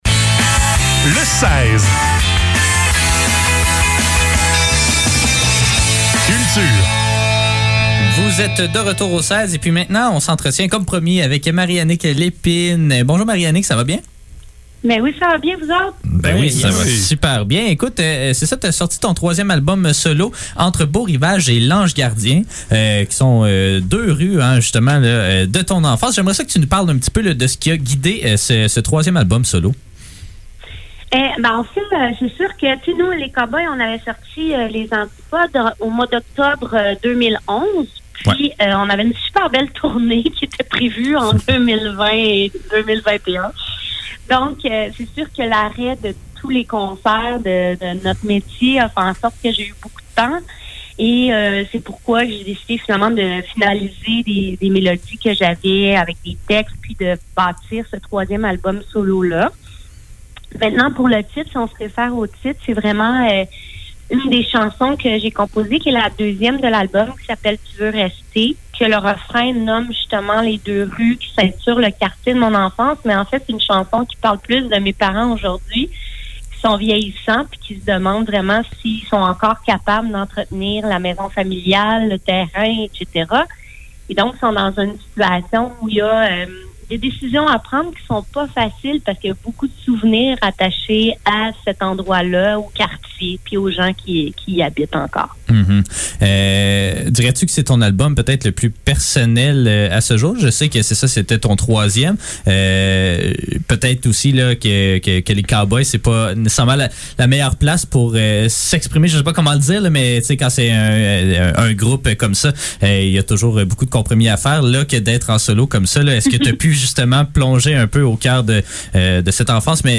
Le seize - Entrevue avec Marie-Annick Lépine - 13 décembre 2021